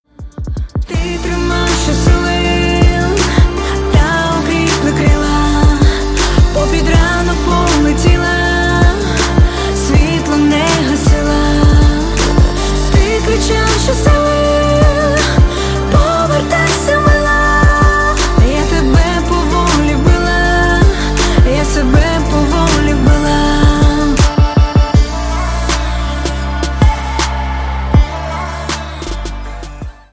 • Качество: 320, Stereo
поп
красивые
женский вокал
спокойные